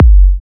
edm-kick-01.wav